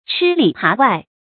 注音：ㄔㄧ ㄌㄧˇ ㄆㄚˊ ㄨㄞˋ
吃里爬外的讀法